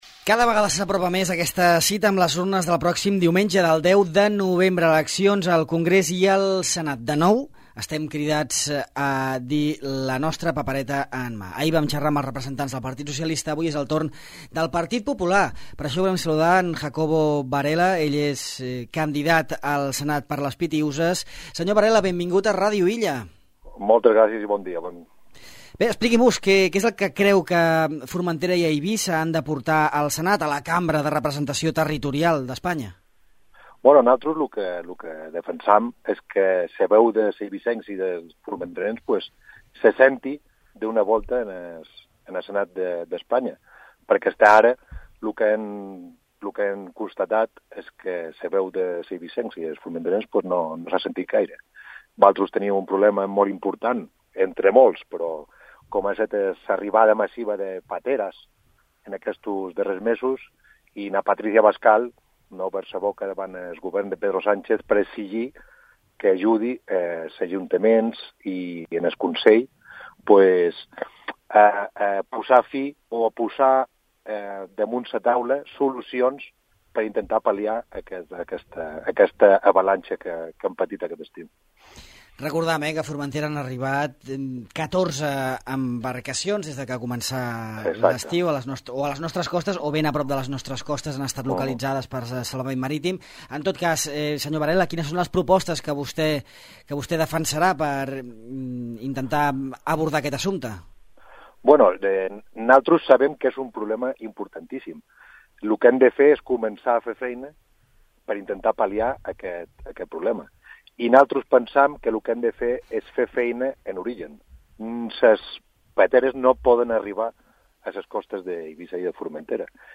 Continuem amb les entrevistes prèvies a les eleccions estatals d’aquest diumenge